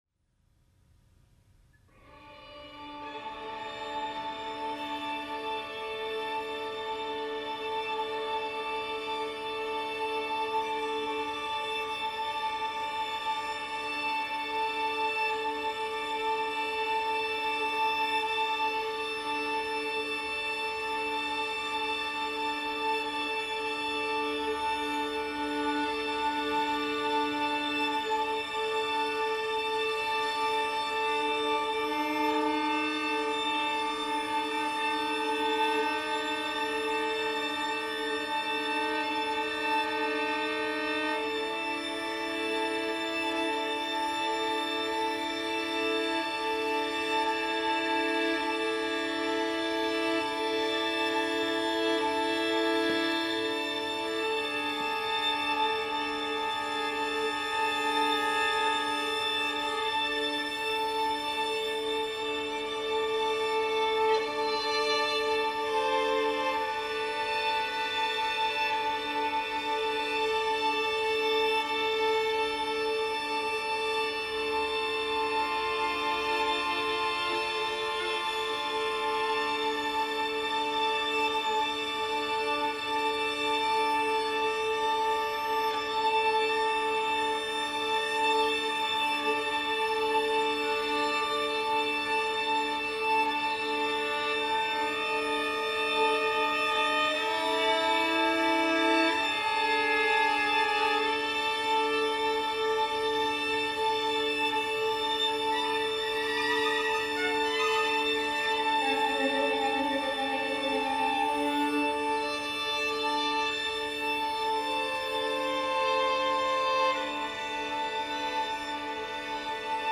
violin
an electro-acoustic piece with a modular violin score andÂ multi-track recordings of violin which create a dense drone.